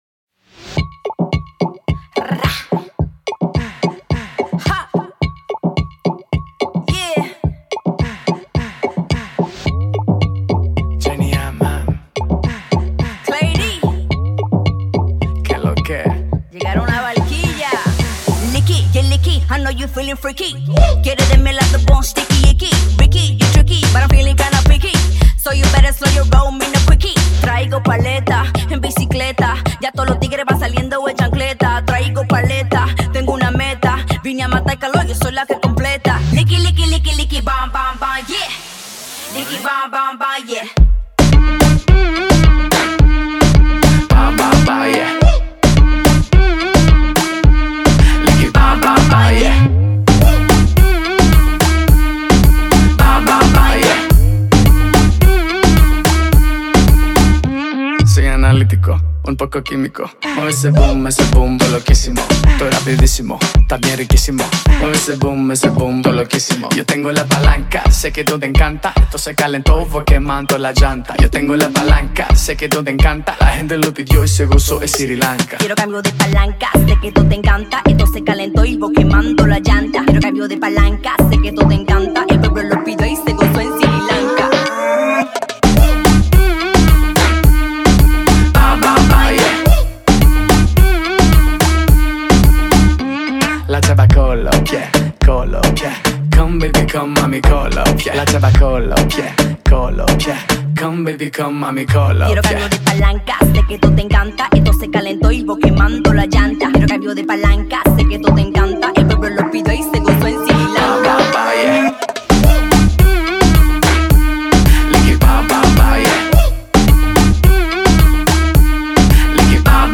это зажигательный трек в жанре латин-поп и реггетон